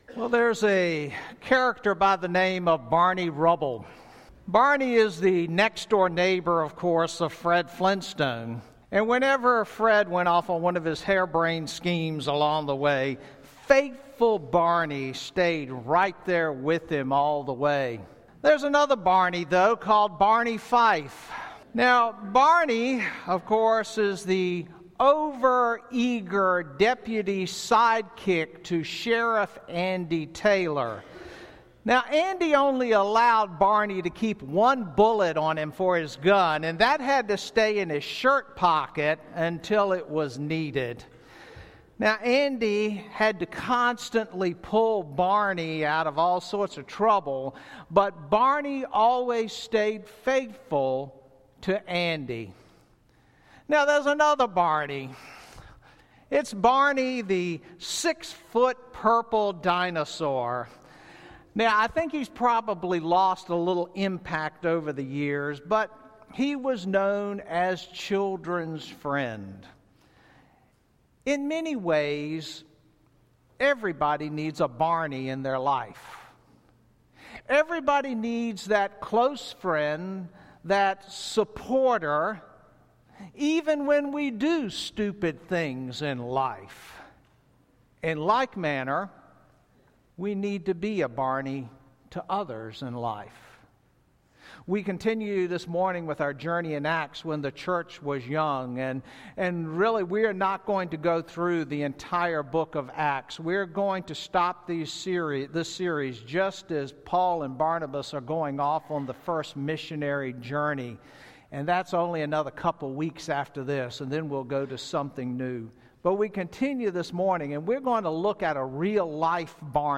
Sermons - Calvary Baptist Bel Air